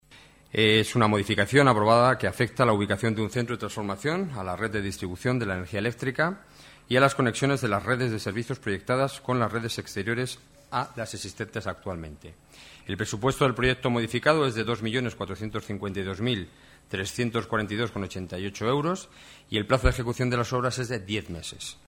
Nueva ventana:Declaraciones del delegado de Seguridad y Emergencias, Enrique Núñez